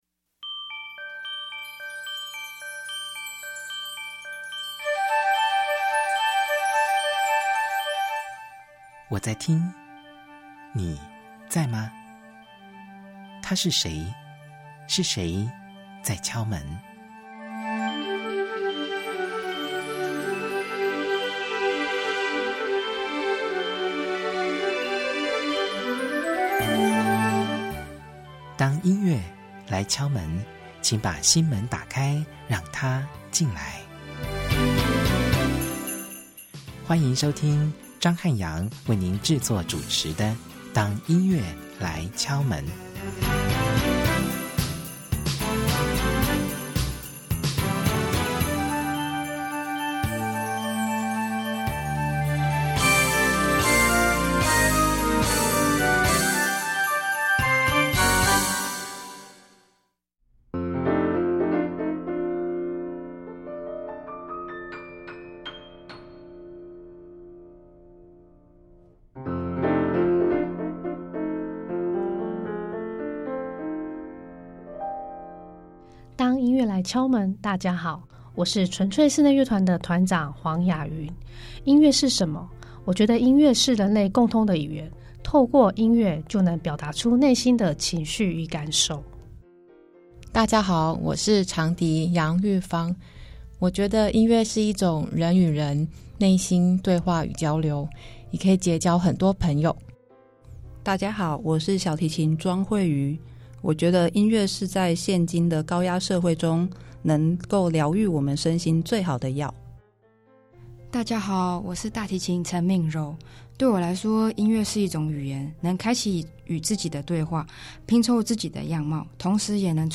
第一單元~本週節目來賓是純粹室內樂團。
將在本集節目中，現場導聆「動畫音樂之旅」音樂會曲目並即席示範演奏